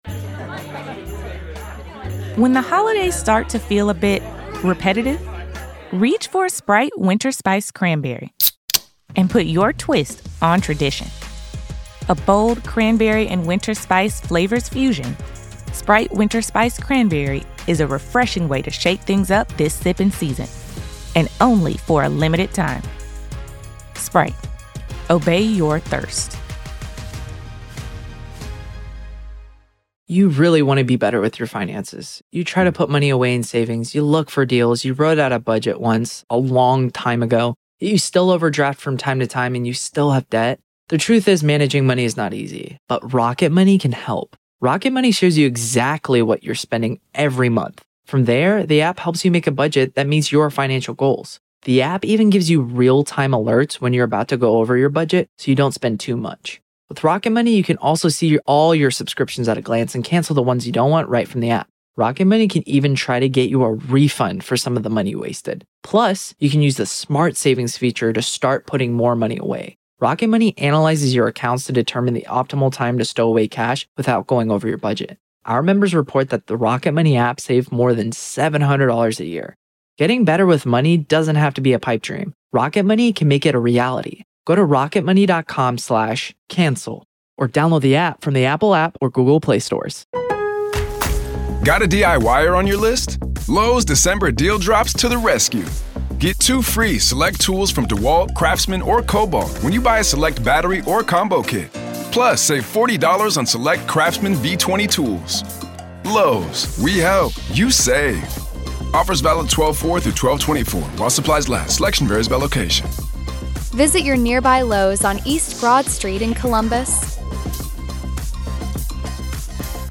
This is audio from the courtroom